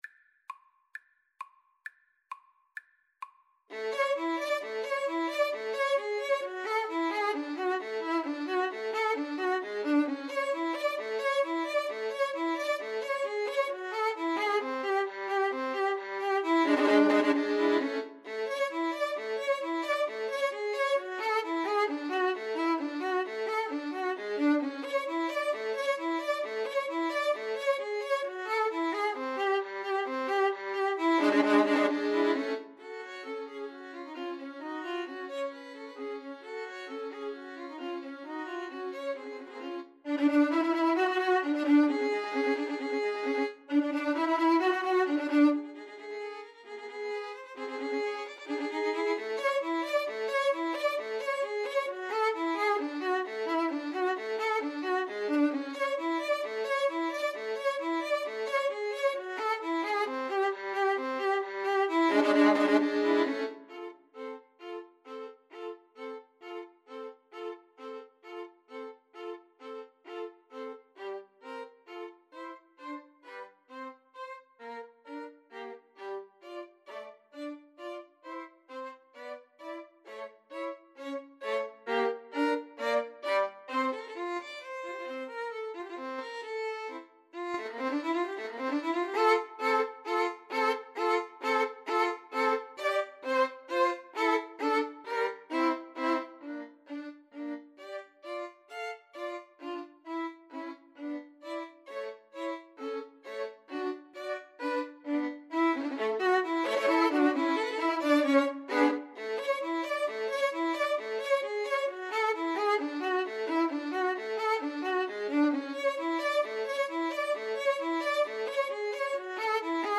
2/4 (View more 2/4 Music)
Violin Trio  (View more Intermediate Violin Trio Music)
Classical (View more Classical Violin Trio Music)